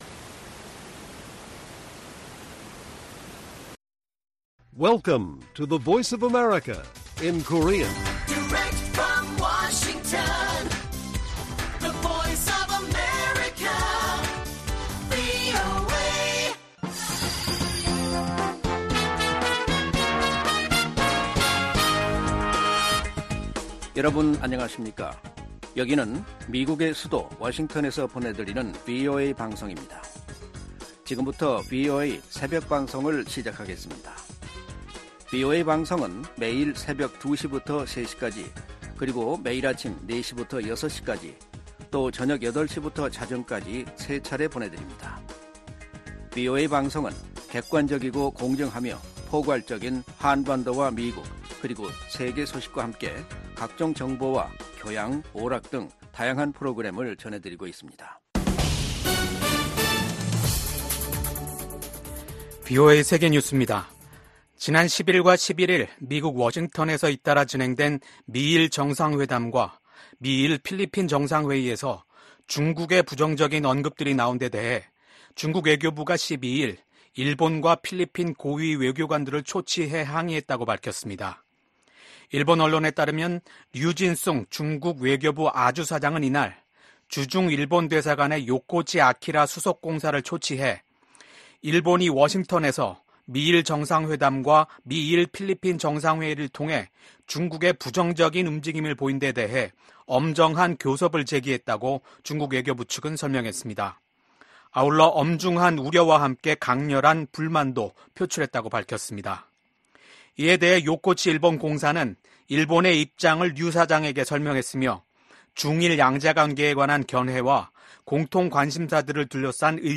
VOA 한국어 '출발 뉴스 쇼', 2024년 4월 13일 방송입니다. 미국과 일본, 필리핀이 11일 워싱턴에서 사상 첫 3자 정상회의를 열고 남중국해와 북한 문제 등 역내 현안을 논의했습니다. 기시다 후미오 일본 총리는 미국 의회 상∙하원 합동회의 연설에서 현재 전 세계적으로 위협받고 있는 자유와 민주주의를 수호하려는 미국의 노력에 일본이 함께하겠다고 말했습니다.